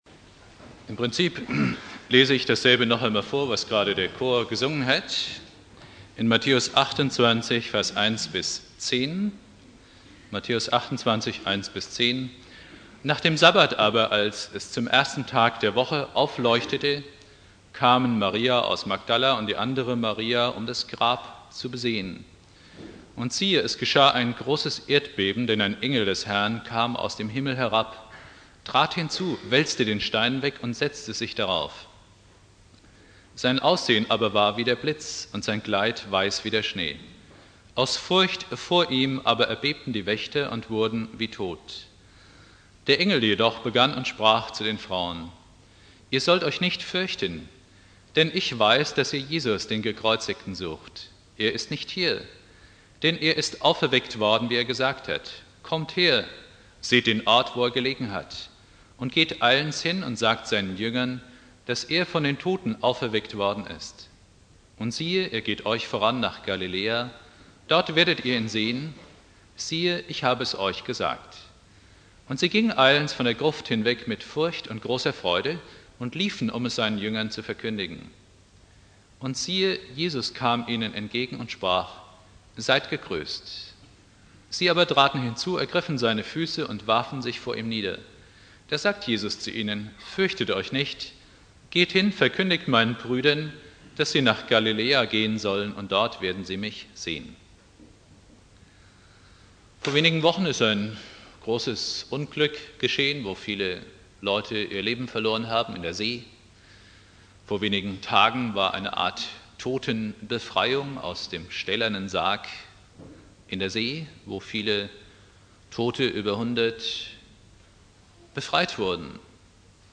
Predigt
Ostersonntag